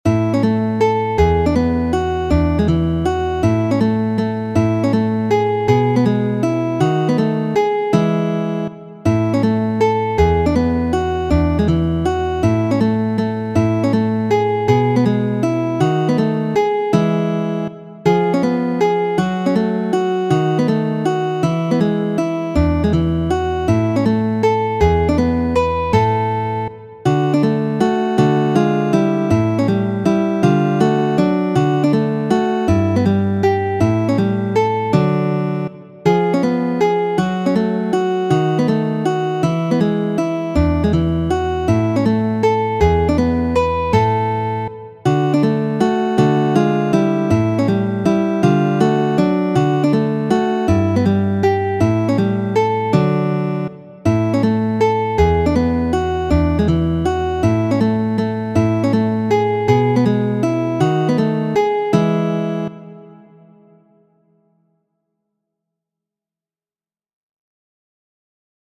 Muziko:
Mazurko, komponita de Antonjo Kano la lastan jarcenton.